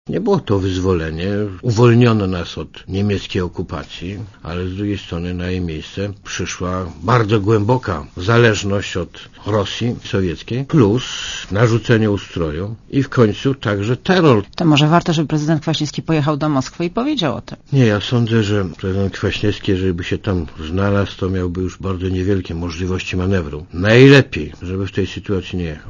Fragment wywiadu z Lechem Kaczyńskim